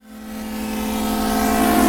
VEC3 FX Athmosphere 13.wav